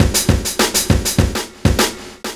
100PERCS02.wav